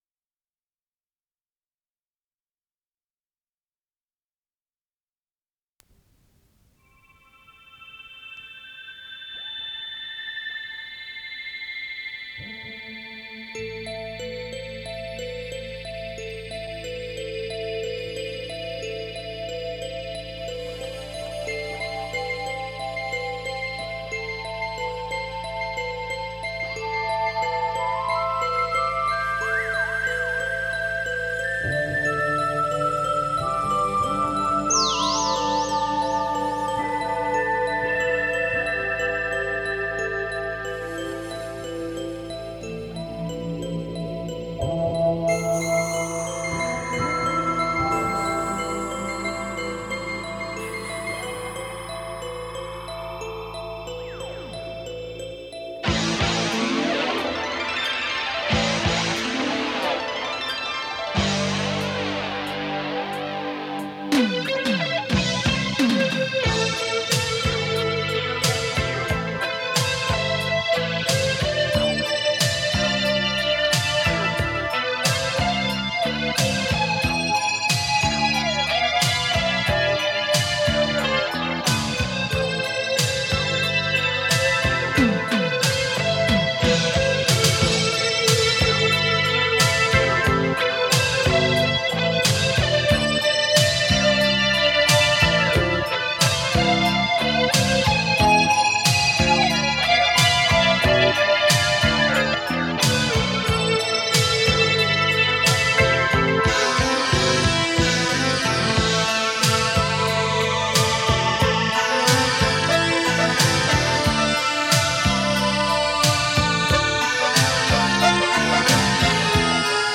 электрогитара
клавишные